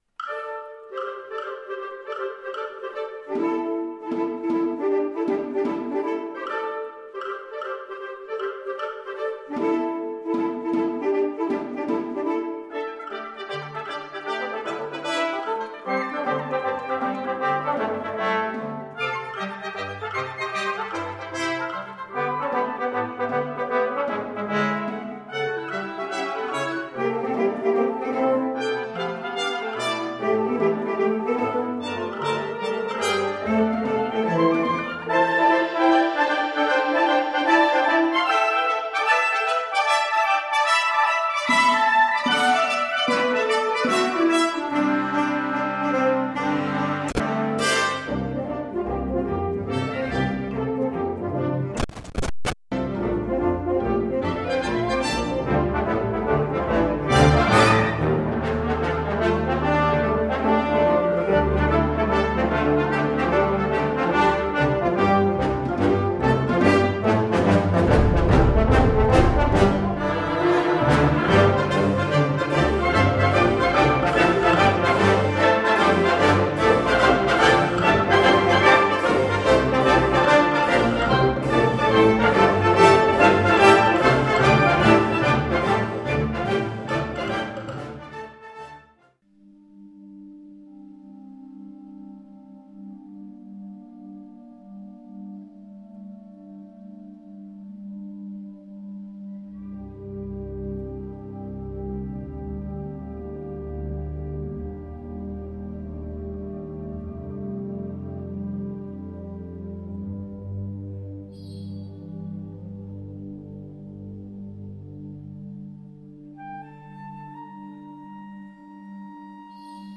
Kategorie Blasorchester/HaFaBra
Unterkategorie Zeitgenössische Bläsermusik (1945-heute)